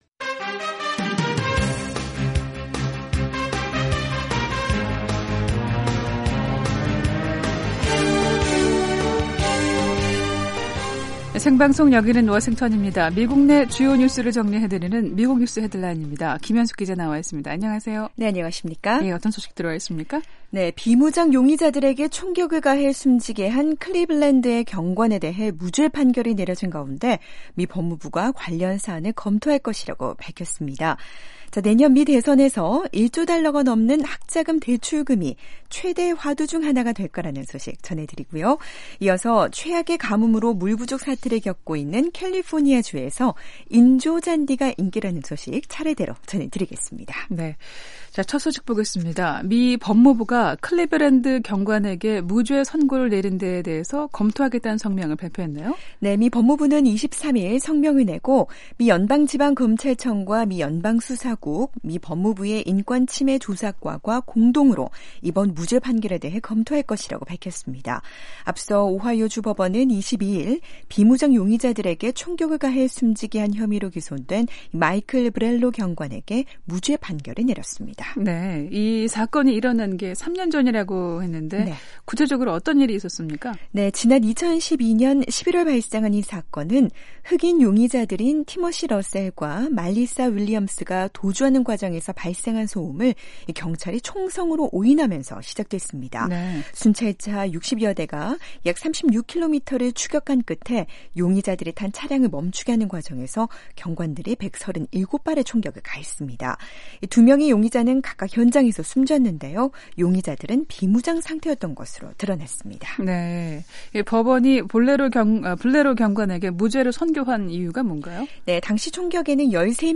미국 내 주요 뉴스를 정리해 드리는 ‘미국 뉴스 헤드라인’입니다. 비무장 용의자들에게 총격을 가해 숨지게 한 클리블랜드의 경관에 대해 무죄 판결이 내려진 가운데 미 법무무가 관련 사안을 검토할 것이라고 밝힌 소식 전해드리고요. 내년 미 대선에서 1조 달러가 넘는 학자금 대출금이 최대 화두 중 하나가 될 거라는 소식 전해 드립니다. 이어서 최악의 가뭄으로 물 부족 사태를 겪고 있는 캘리포니아 주에서 인조 잔디가 인기라는 소식 차례로 전해드리겠습니다.